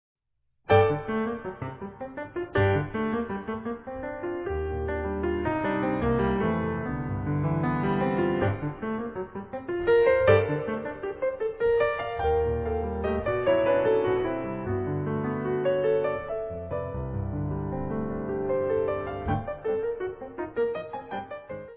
A piece for solo piano.